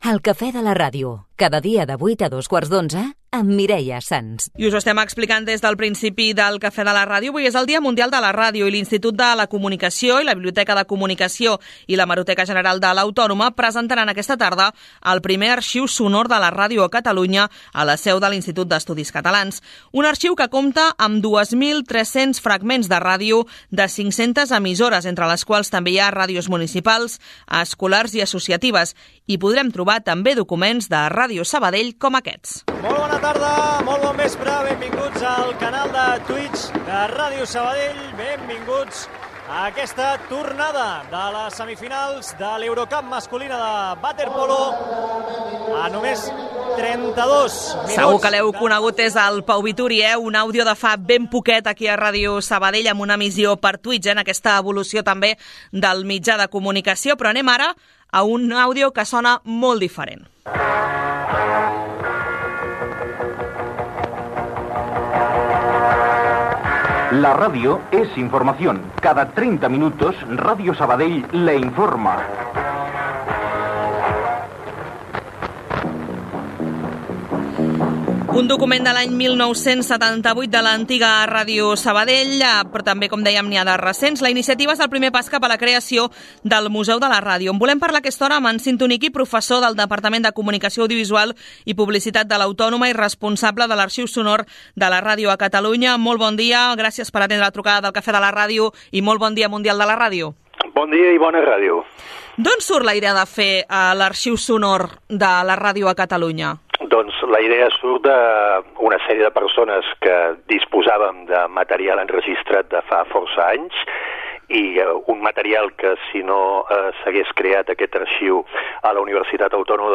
369252cdf93827f52b07e774b829064a2a80ce58.mp3 Títol Ràdio Sabadell (municipal) Emissora Ràdio Sabadell (municipal) Titularitat Pública municipal Nom programa Cafè de la ràdio Descripció Indicatiu del programa.